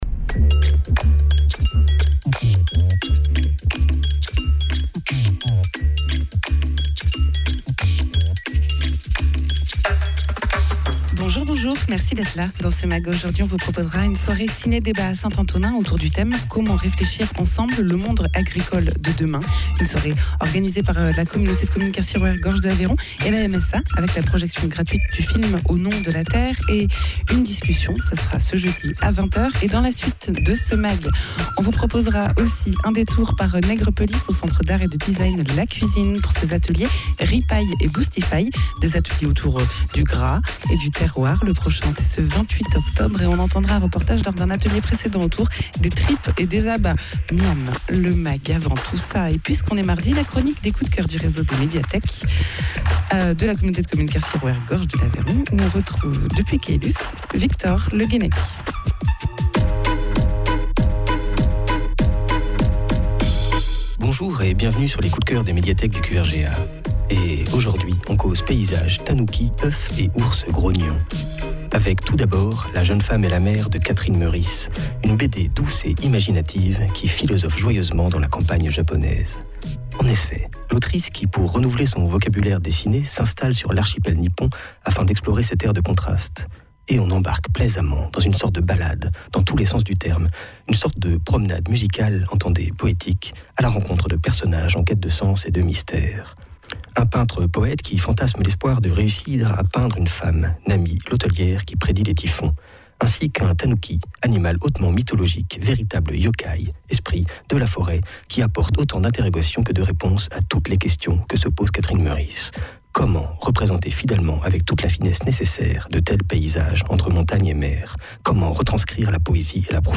La projection sera suivie d’un temps d’échanges et de réflexions autour des enjeux agricoles soulevés par le film, ce jeudi à 20h à la salle des congrès de la mairie de St Antonin Noble Val. Egalement dans ce mag, un reportage à La Cuisine, centre d’art et de design lors d’un atelier "ripaille et boustifaille".